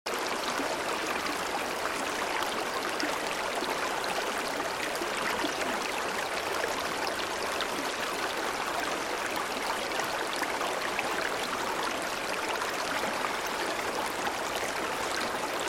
Natural sounds for relaxation and sound effects free download
Natural sounds for relaxation and meditation, the relaxing sound of valley water 🌿 It is the sound of flowing water, filled with the peaceful and quiet afternoon sunlight.